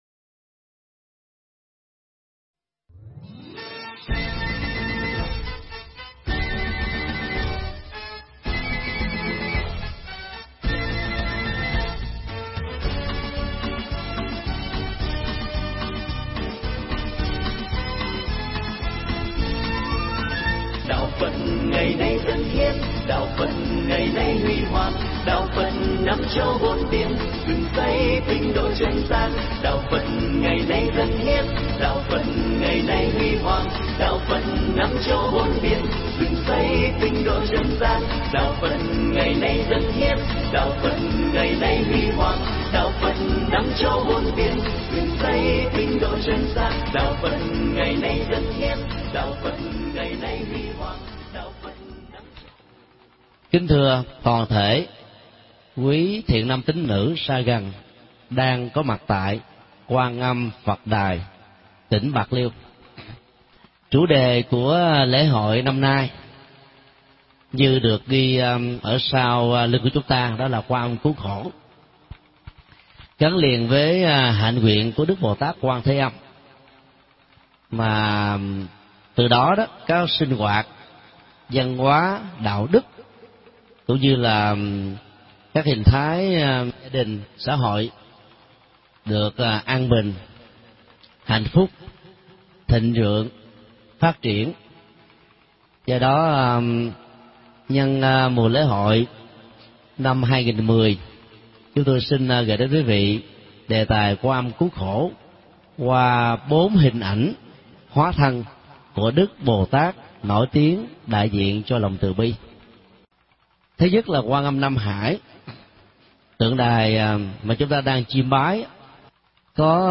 Thuyết pháp Quan Âm cứu khổ qua bốn ứng thân
tại Lễ Hội Quan Âm tại Bạc Liêu